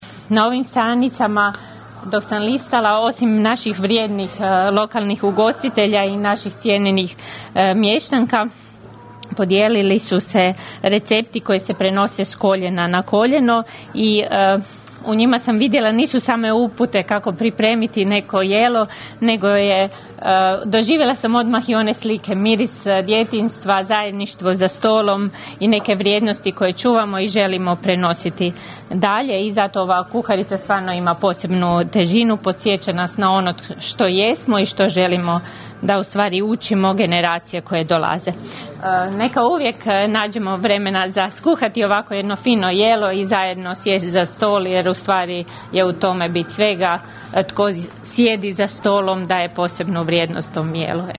U restoranu Dorina u Plominu danas je predstavljena „Fianona – riblja kuharica“, izdanje Turističke zajednice Općine Kršan.
Načelnica Općine Kršan Ana Vuksan zahvalila je svima koji su sudjelovali u realizaciji kuharice: (
ton – Ana Vuksan), rekla je kršanska općinska načelnica.